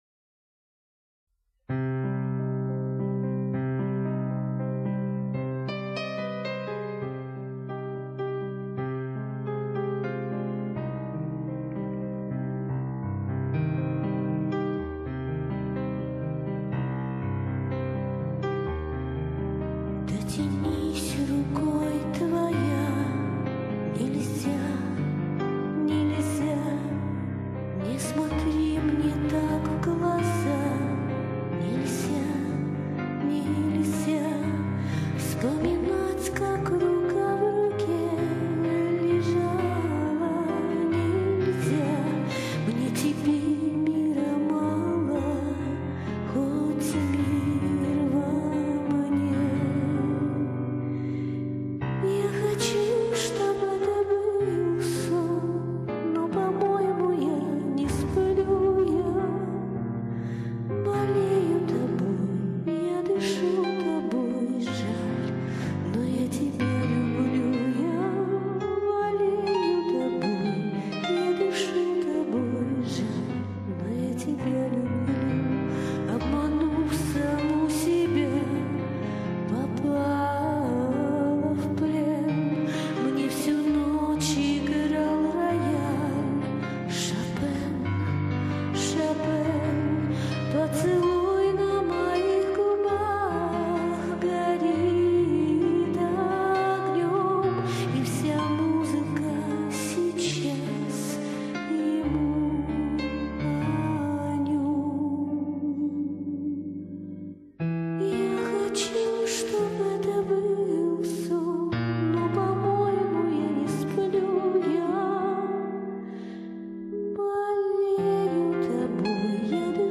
Очень приятный голос!